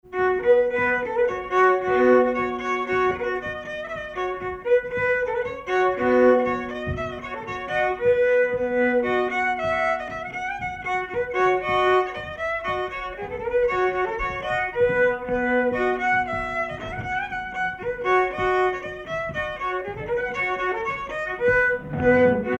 Danse rapide
circonstance : bal, dancerie ;
Pièce musicale inédite